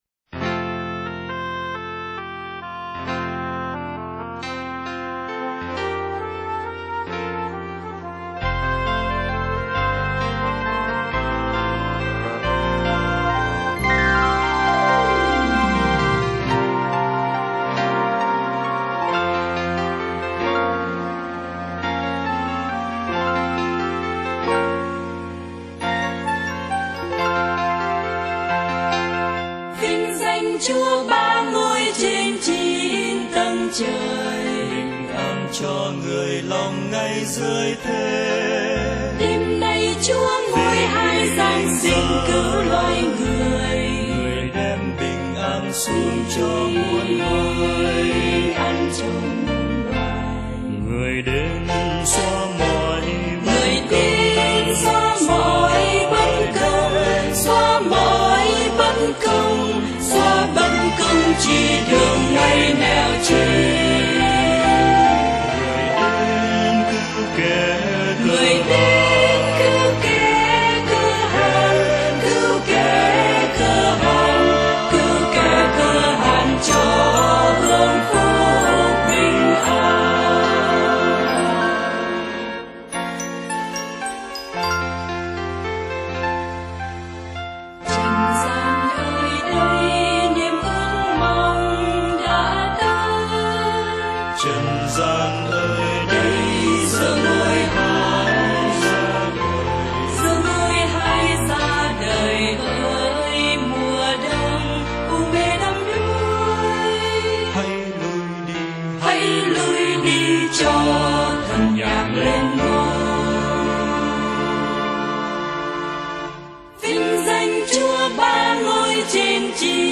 Gởi bạn bài này trình bày tứ ca, hòa âm hơi khác